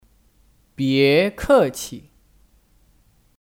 别客气 (Bié kèqi 别客气)